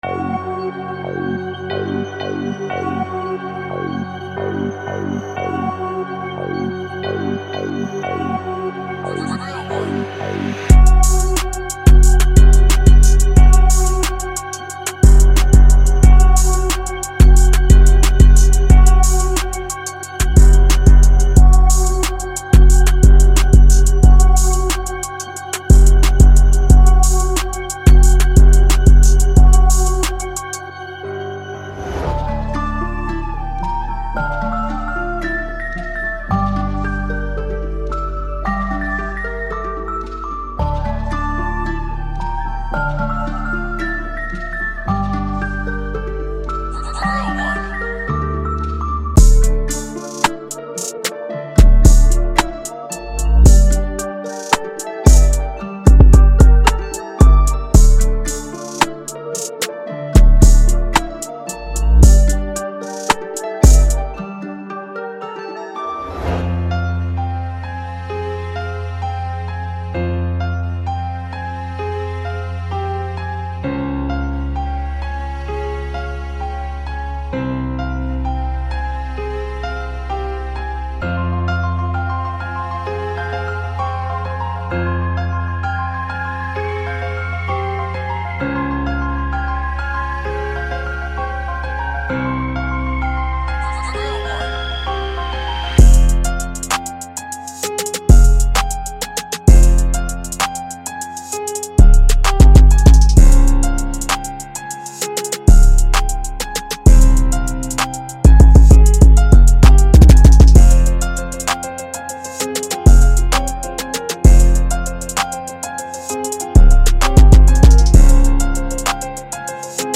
مجموعه فایل میدی های هت